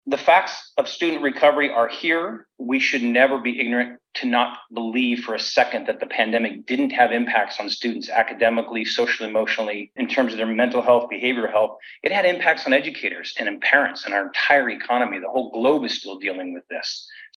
OLYMPIA, WA – As the 2023 Washington State Legislative Session begins today, State Superintendent Chris Reykdal described the state of Washington’s K–12 education system in an annual public address from the Office of Superintendent of Public Instruction headquarters in Olympia.